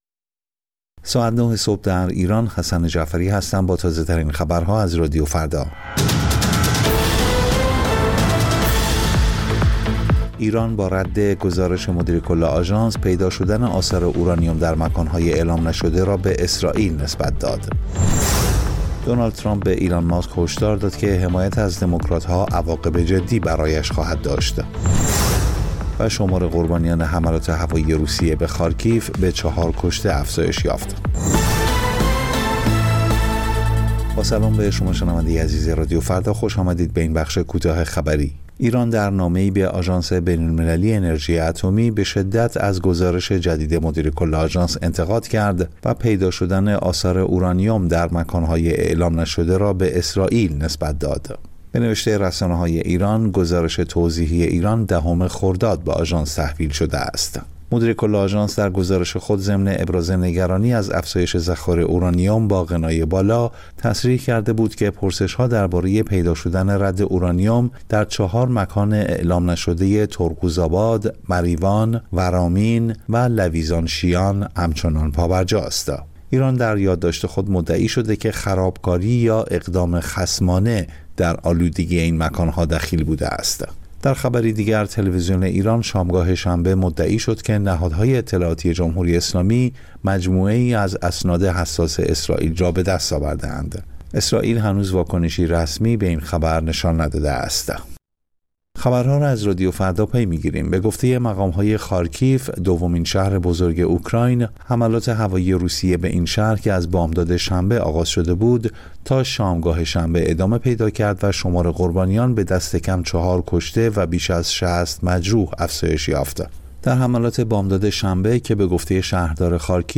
سرخط خبرها ۹:۰۰